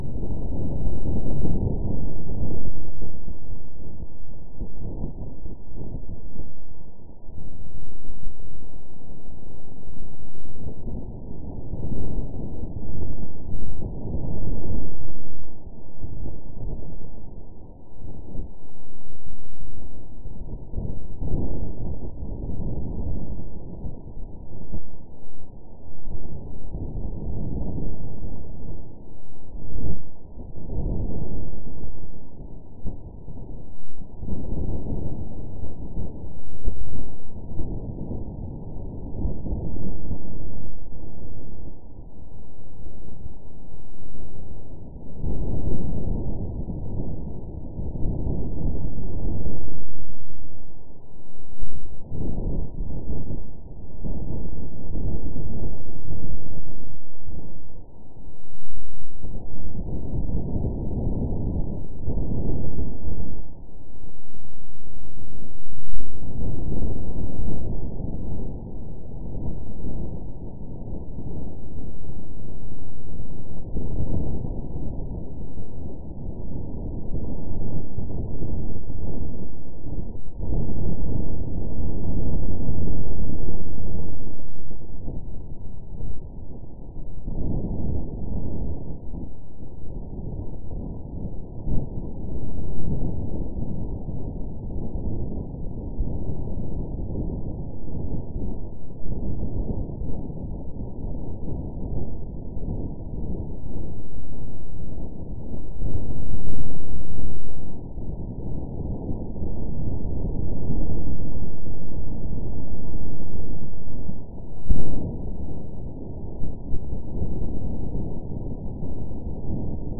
1jour1actu te fait écouter le bruit du vent martien !
Du vent dans les panneaux solaires…
Ce sont ces 2 appareils, ultraprécis, qui ont enregistré le son du vent sur Mars. Le vent a fait vibrer les panneaux solaires d’InSight : c’est cette vibration que tu peux entendre dans les 2 sons ci-dessous (mets des écouteurs pour bien entendre ) :
As-tu entendu ce grondement lointain ?
Pour que l’oreille humaine entende ce son particulier, les chercheurs ont donc amplifié ces vibrations : en effet, sur Mars, le manque d’air modifie la perception des sons.